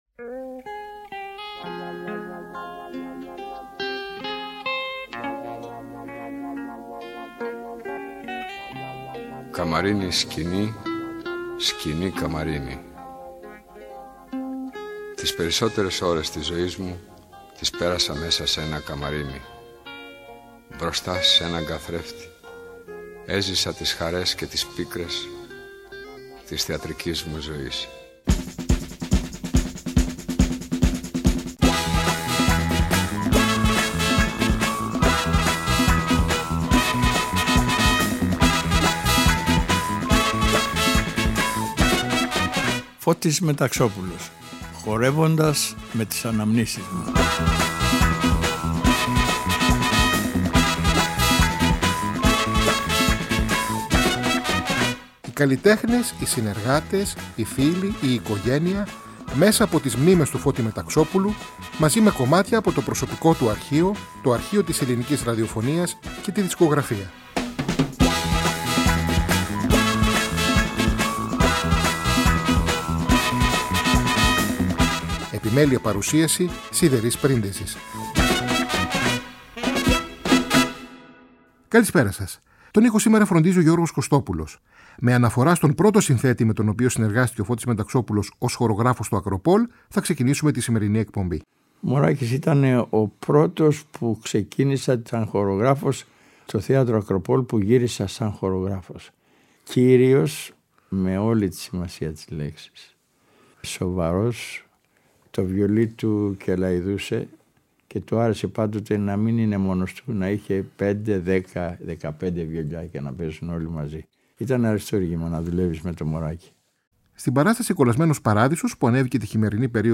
ΔΕΥΤΕΡΟ ΠΡΟΓΡΑΜΜΑ Αφιερώματα Μουσική Συνεντεύξεις